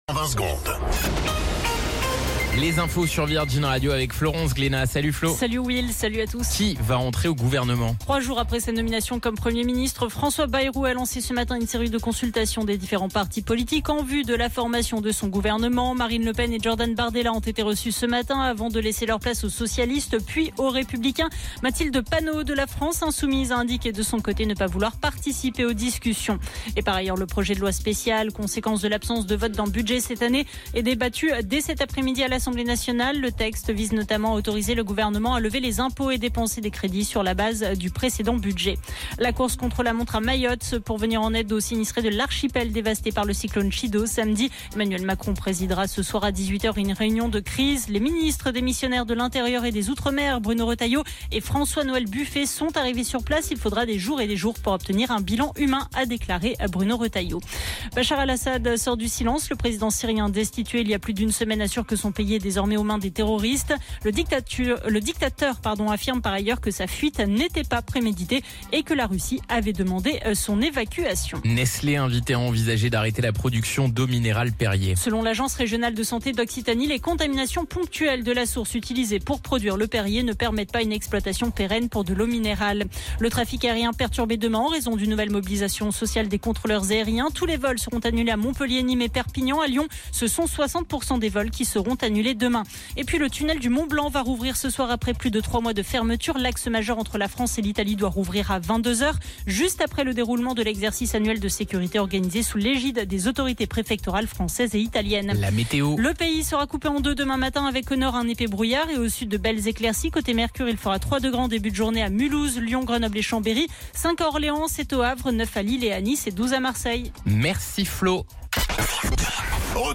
Flash Info National 16 Décembre 2024 Du 16/12/2024 à 17h10 .